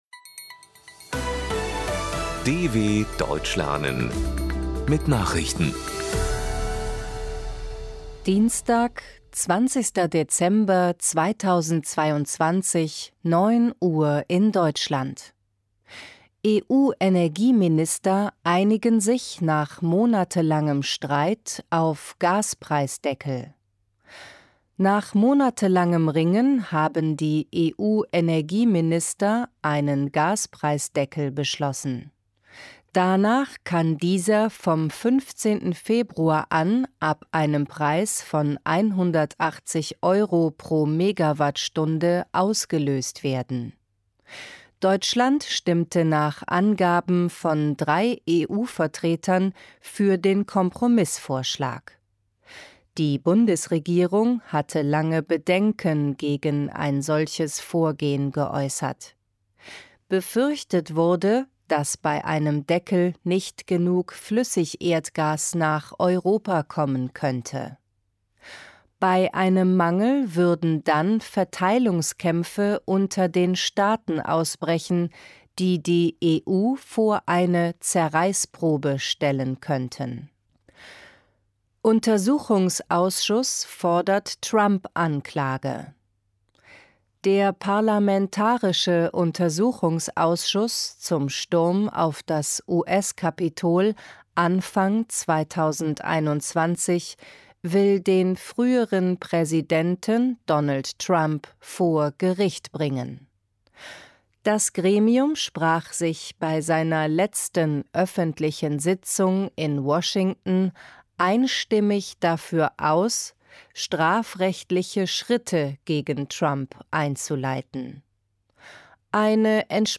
20.12.2022 – Langsam gesprochene Nachrichten
Trainiere dein Hörverstehen mit den Nachrichten der Deutschen Welle von Dienstag – als Text und als verständlich gesprochene Audio-Datei.